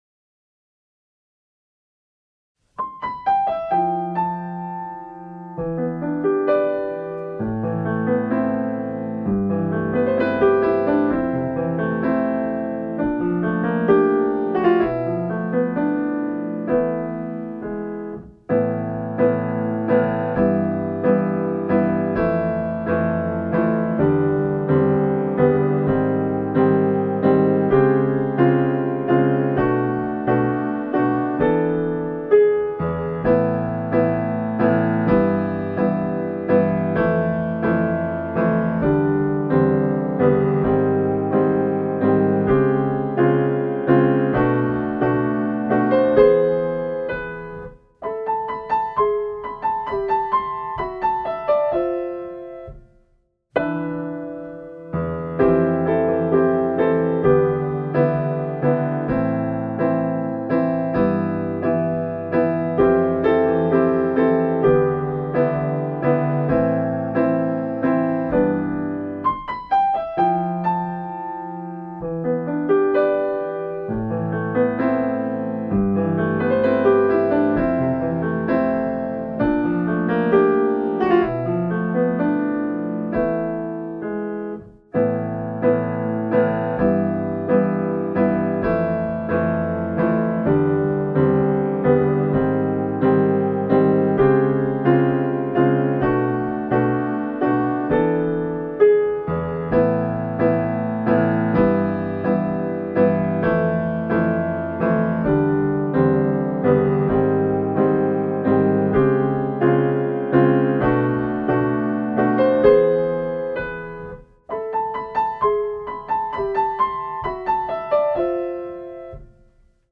【Instrumental / リマスター版2025】 mp3 DL ♪